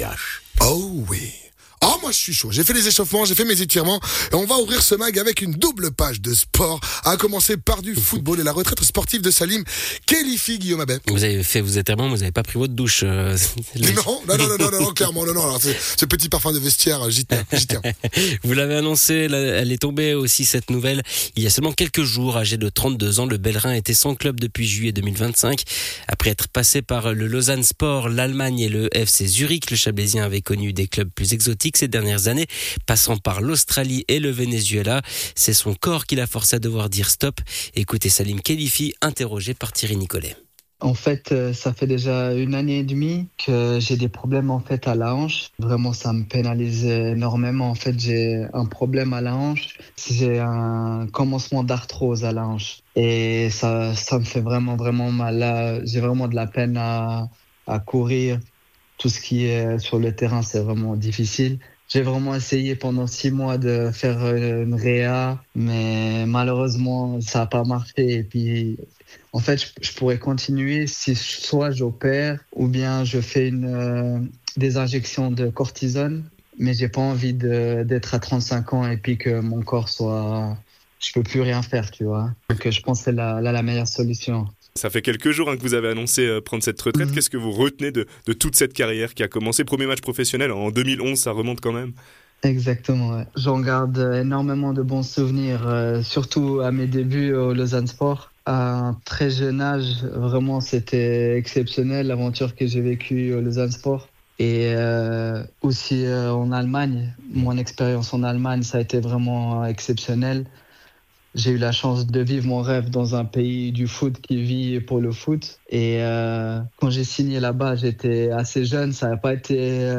Intervenant(e) : Salim Khelifi, ancien footballeur professionnel